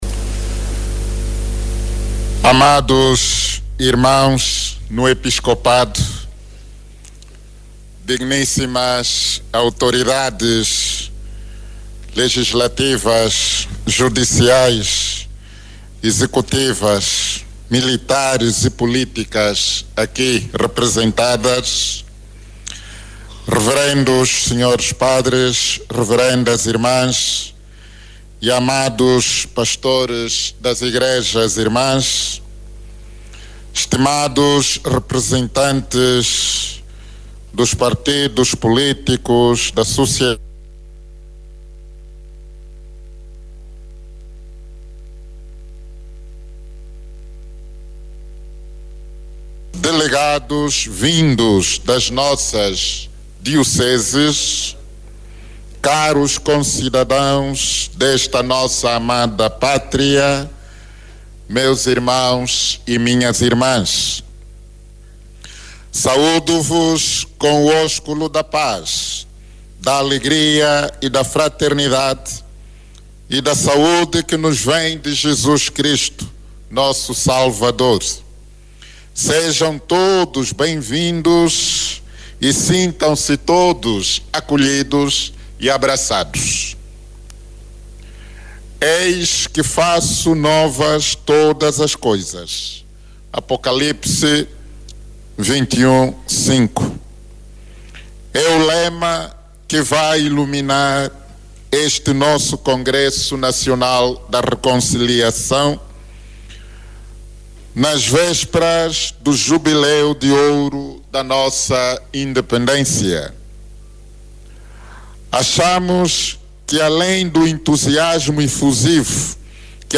Oiça o discurso de abertura na voz do Presidente da CEAST.
DOM-IMBAMBA-ABERTURA-CONGRECO.mp3